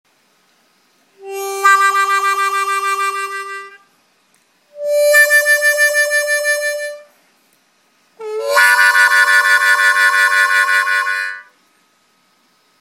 Эффект тремоло.
По сути, эффект тремоло – это быстрый эффект «вау-вау».
tremolo.mp3